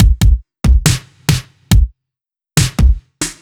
Index of /musicradar/french-house-chillout-samples/140bpm/Beats
FHC_BeatA_140-01_KickSnare.wav